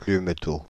Plumetot (French pronunciation: [plym(ə)to]